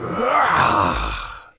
sceleton_atack2.ogg